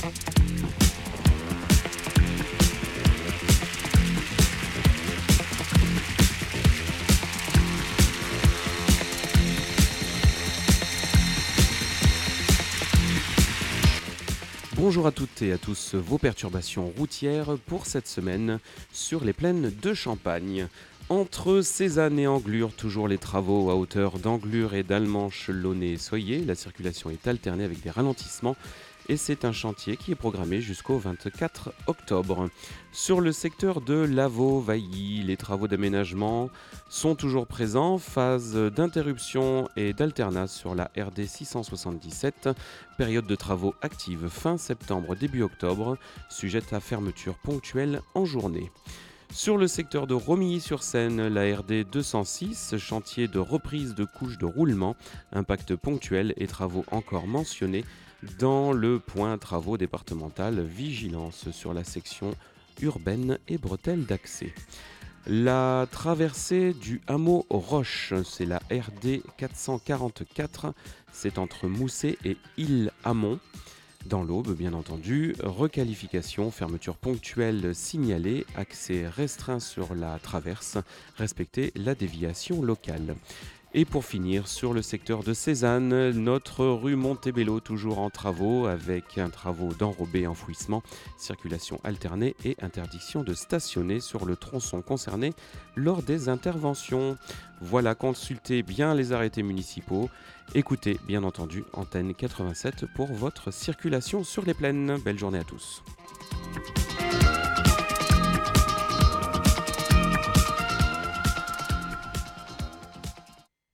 Bienvenue dans l’InfoRoute des Plaines – votre bulletin circulation du matin !Chaque jour, nous vous accompagnons sur les routes des Plaines de Champagne avec un point complet sur les conditions de circulation, afin de vous aider à voyager en toute sérénité.